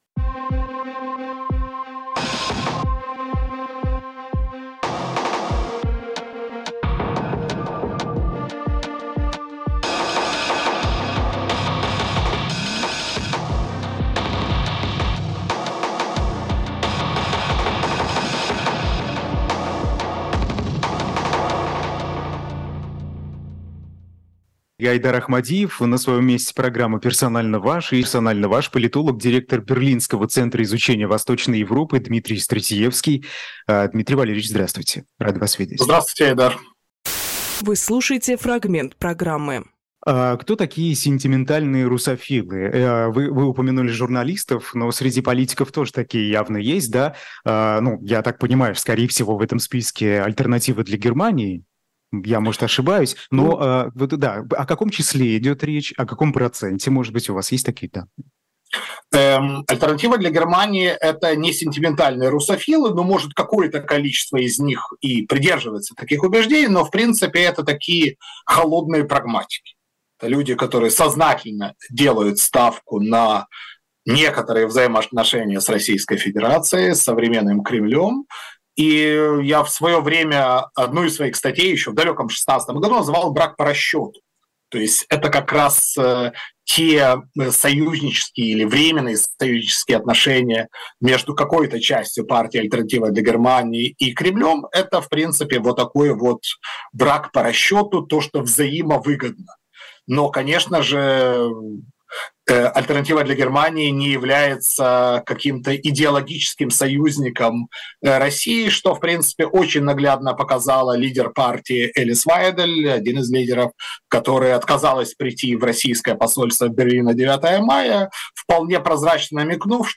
Фрагмент эфира от 15.11.23